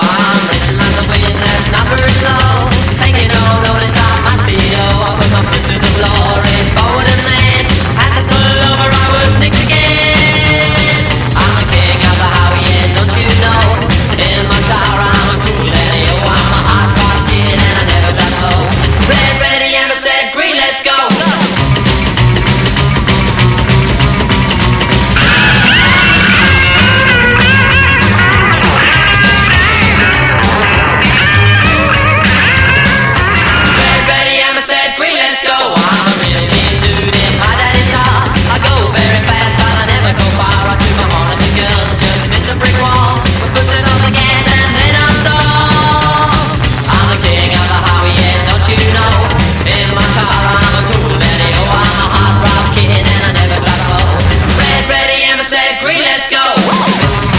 ROCK / PUNK / 80'S～ / NEO ROCKABILLY (UK)
軽快に弾むビートに甘いメロディーが弾ける、ジャケットどおりにポップでカラフルなロッカビリー・ナンバーがぎっしり。